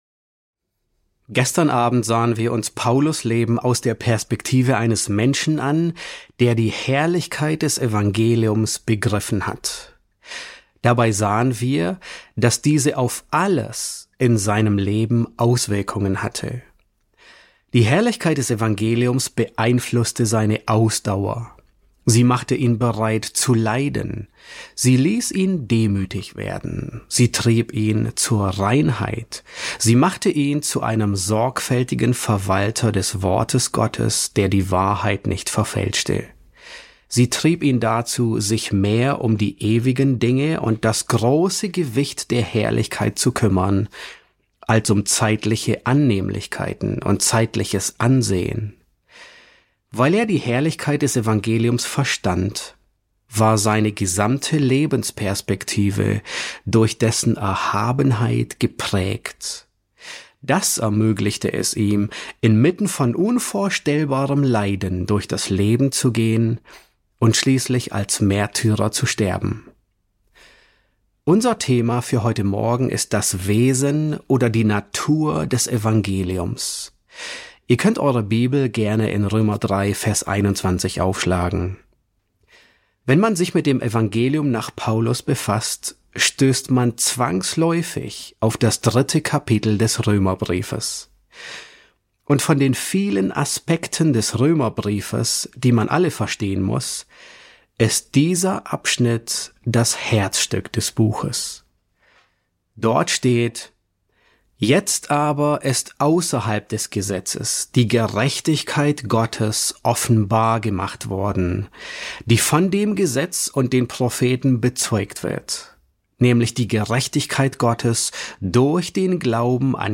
S8 F2 | Das Evangelium stillt die Not des Sünders ~ John MacArthur Predigten auf Deutsch Podcast